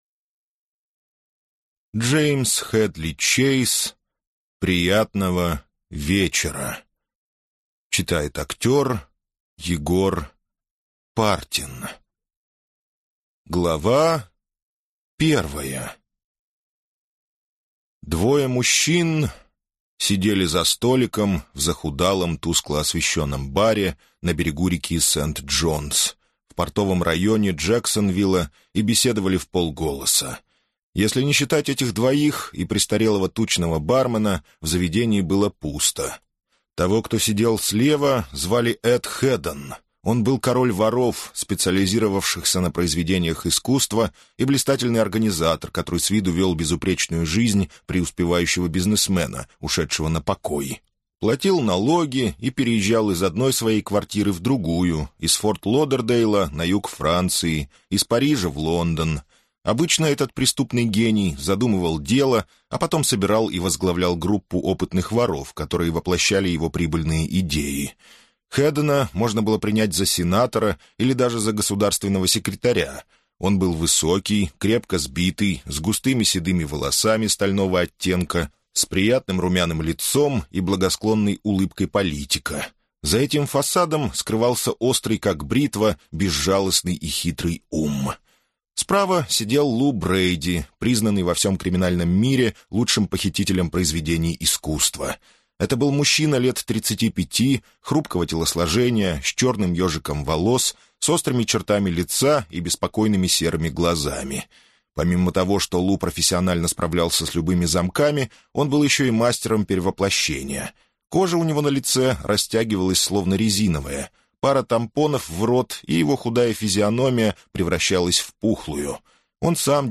Маршрут случайных пассажиров (слушать аудиокнигу бесплатно) - автор Галина Романова